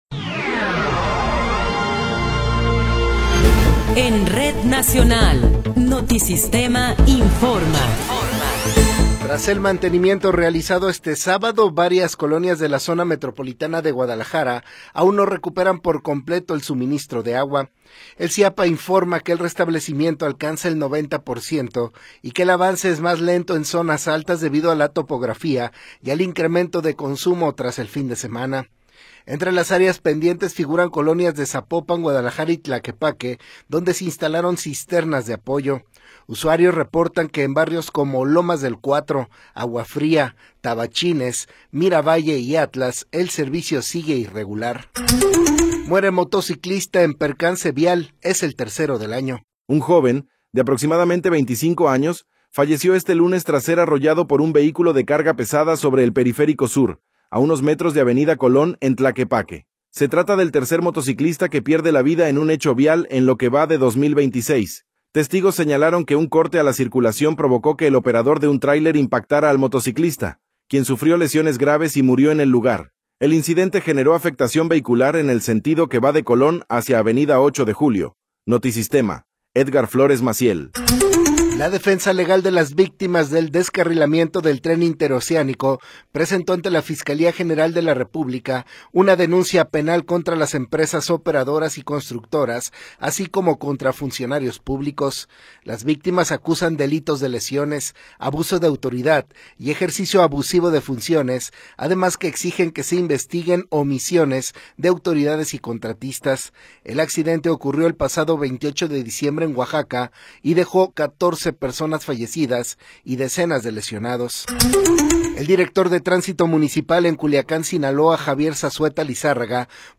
Noticiero 18 hrs. – 5 de Enero de 2026